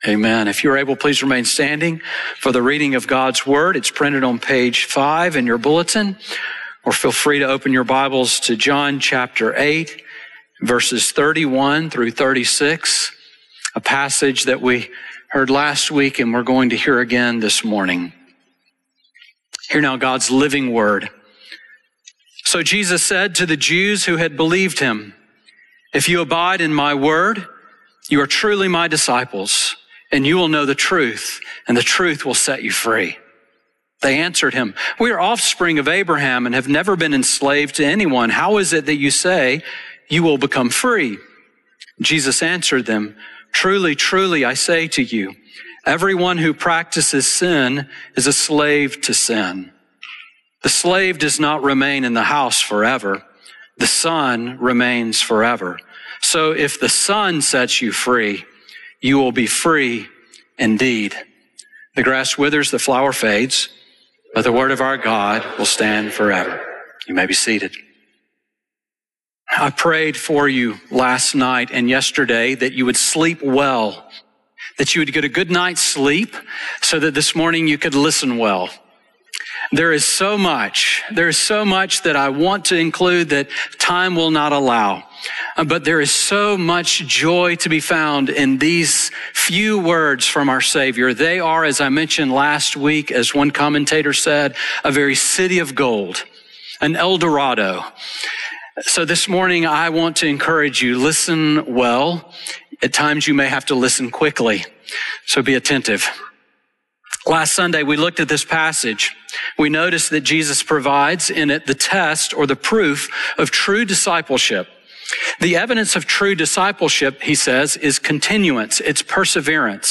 Sermon on John 8:31-36 from January 18